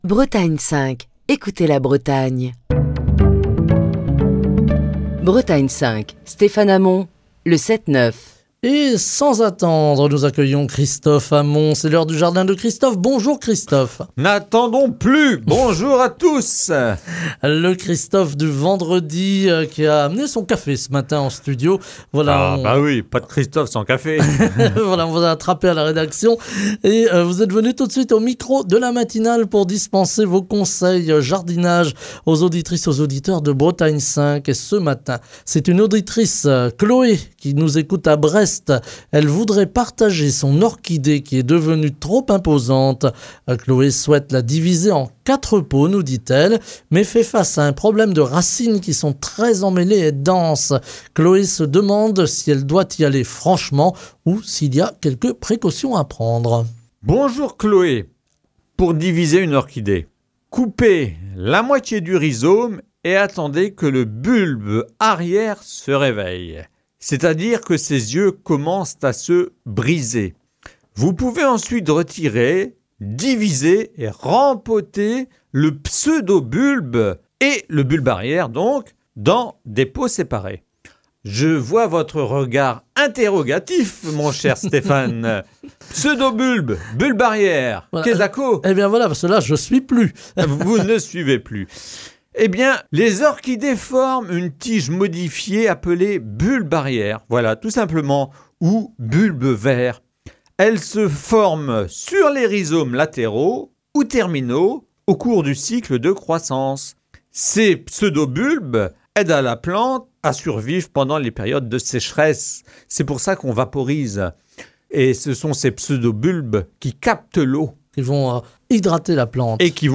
répond aux questions des auditeurs - Vendredi 28 mars 2025 (Rediffusion)